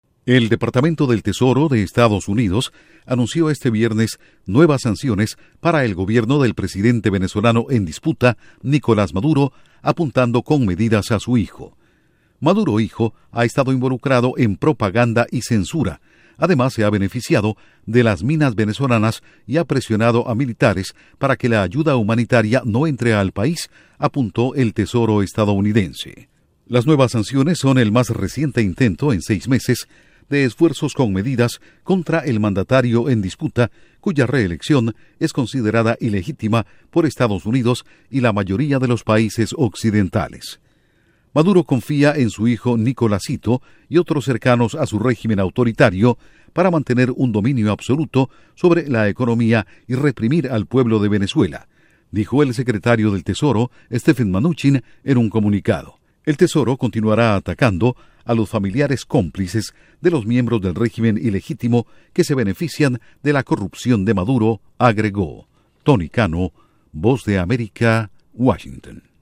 Estados Unidos sanciona a hijo del presidente en disputa de Venezuela. Informa desde la Voz de América en Washington